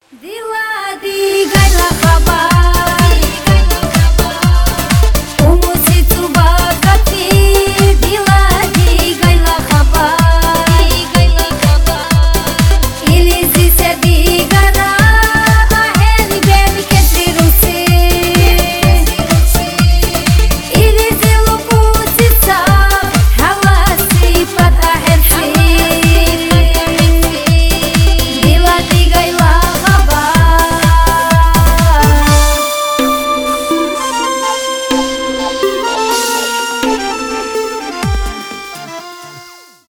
поп , эстрадные , кавказские